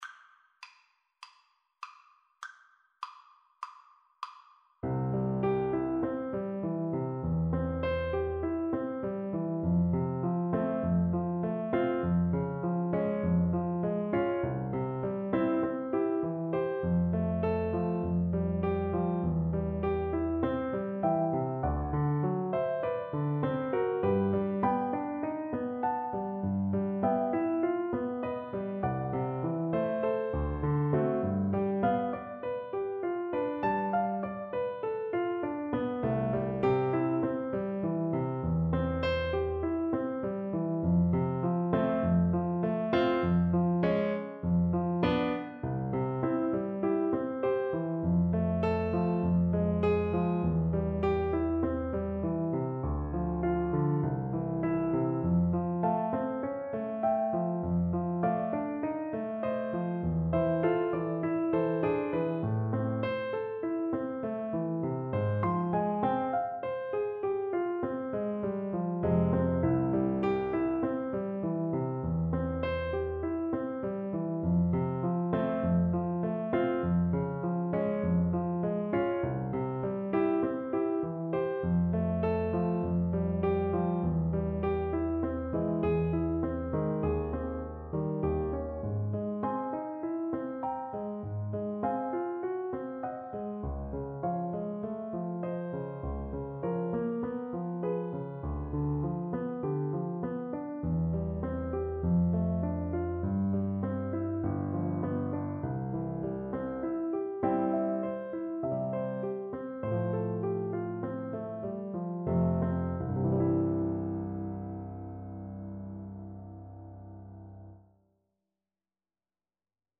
Play (or use space bar on your keyboard) Pause Music Playalong - Piano Accompaniment Playalong Band Accompaniment not yet available transpose reset tempo print settings full screen
French Horn
C major (Sounding Pitch) G major (French Horn in F) (View more C major Music for French Horn )
Zart.
2/2 (View more 2/2 Music)
Classical (View more Classical French Horn Music)